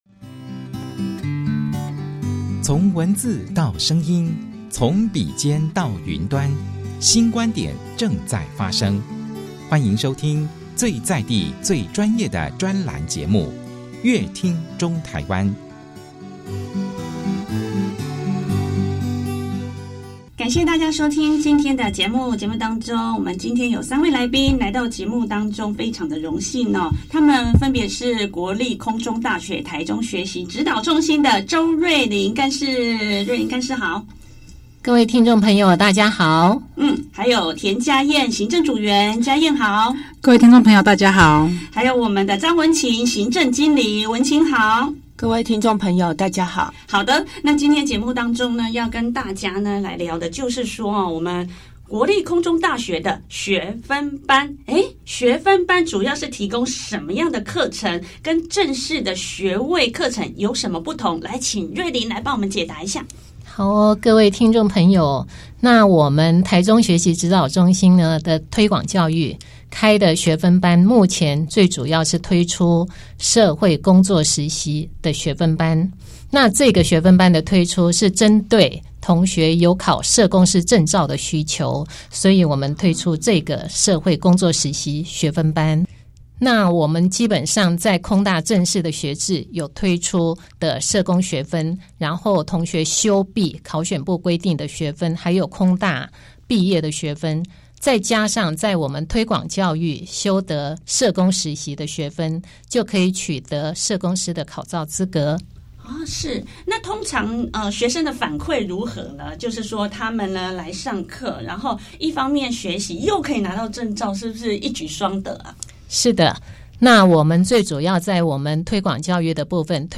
想知道更多精彩的訪談內容，請鎖定本集節目。